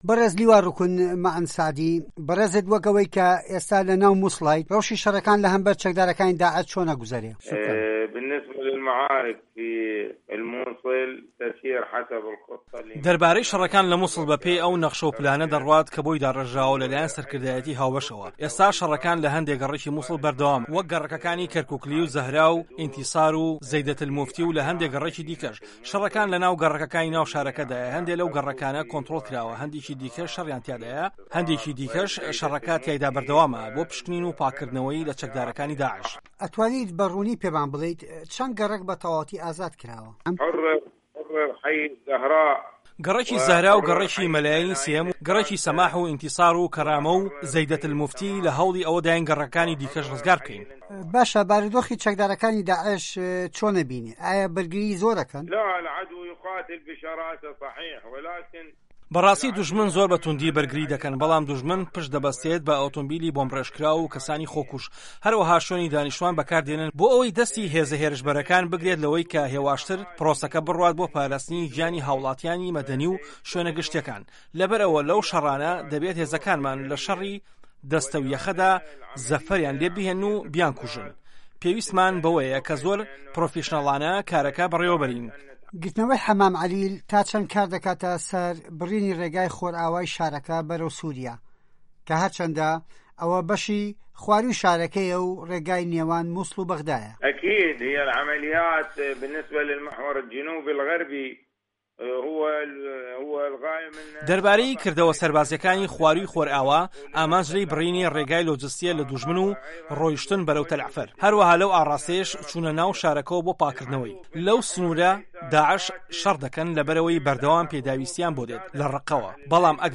وتووێژ لەگەڵ لیوا روکن مه‌عه‌ن ئه‌لسه‌عدی